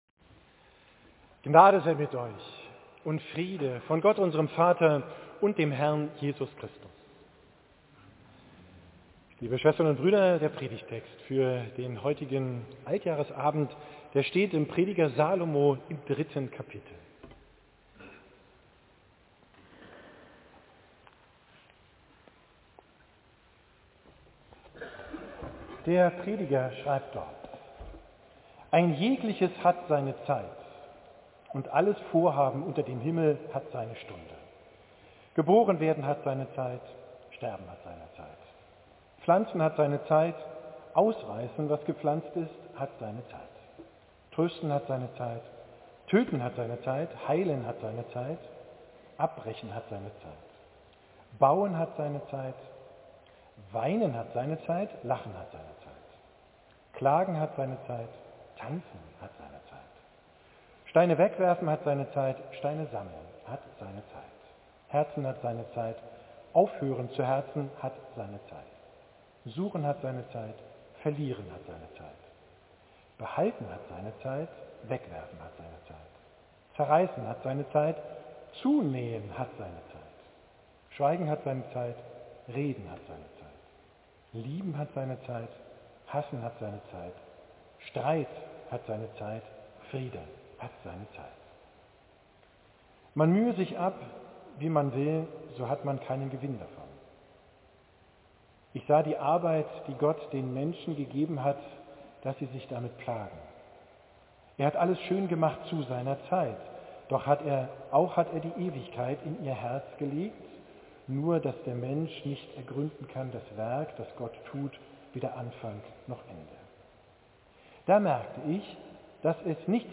Predigt vom Silvestertag, am 31.